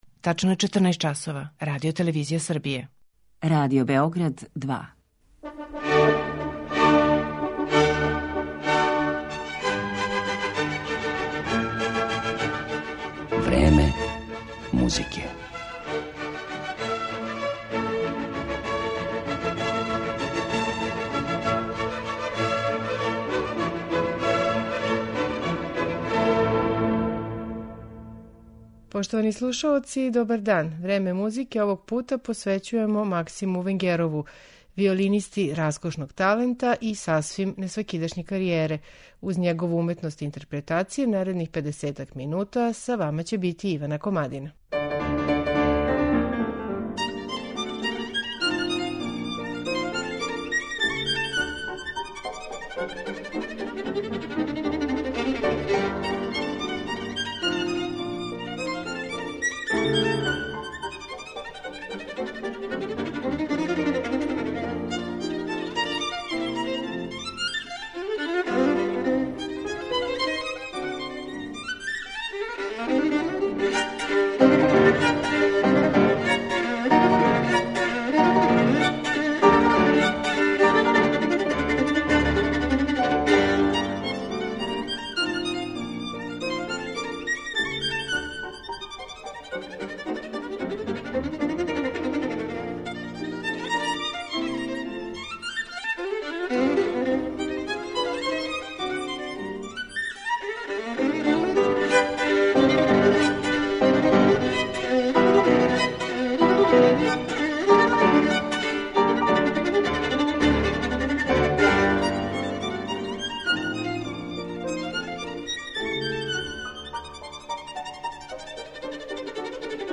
виолинисти